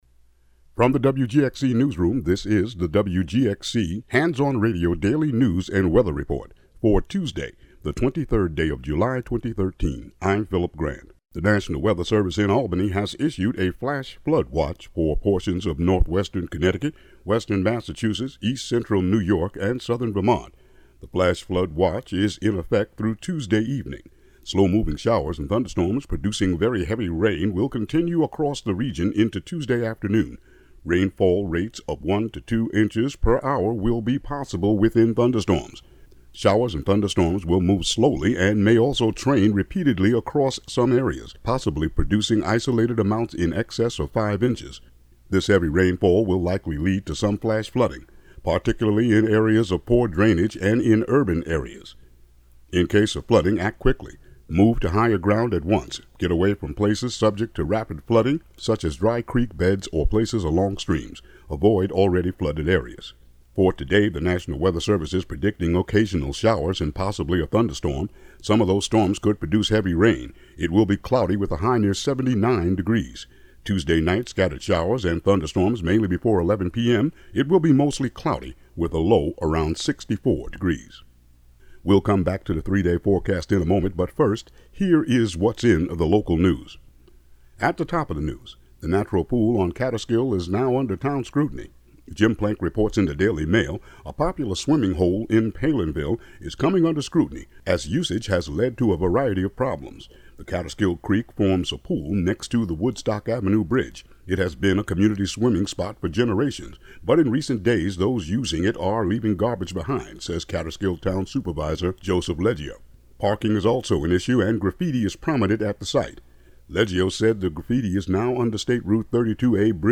Local news and weather for Tuesday, July 23, 2013.